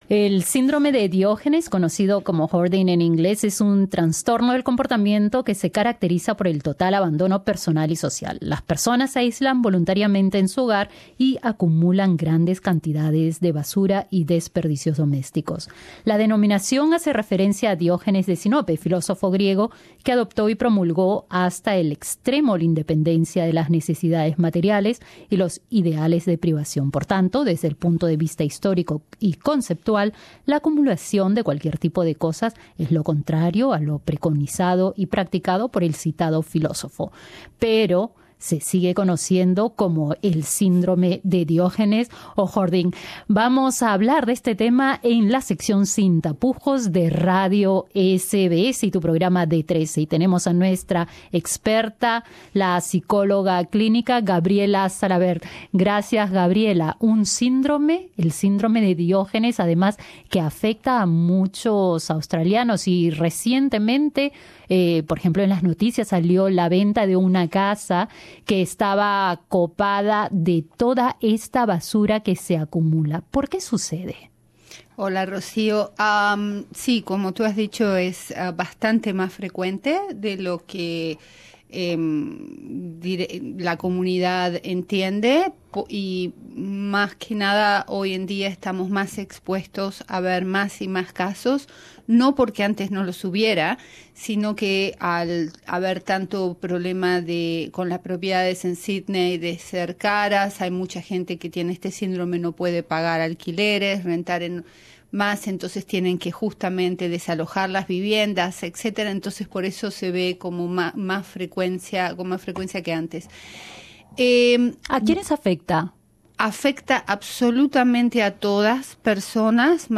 Se trata del Síndrome de Diógenes que afecta a todas las personas, pero especialmente a mujeres mayores solas. En SIN TAPUJOS nuestra sección sobre sexualidad y relaciones interpersonales conversamos sobre este síndrome, conocido en inglés como "hoarding", con la psicóloga clínica